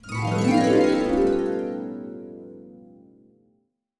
Magical Harp (1).wav